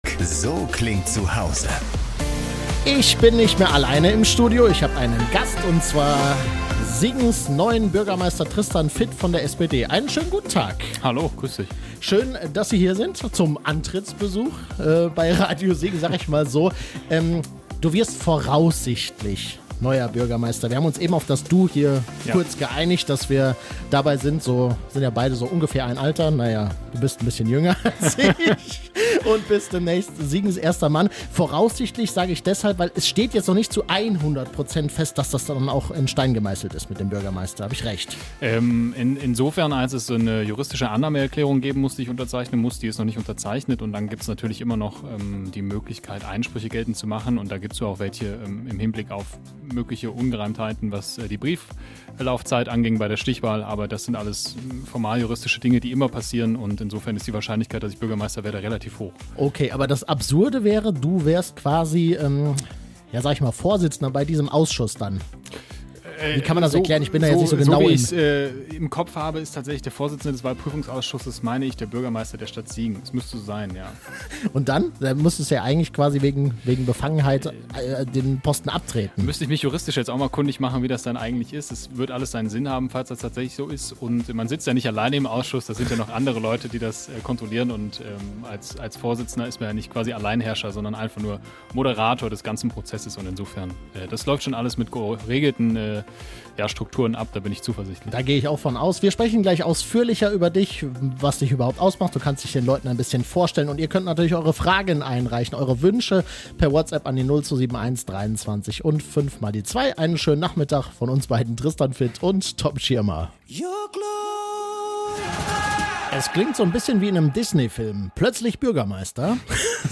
Das Interview im Podcastformat findet ihr hier.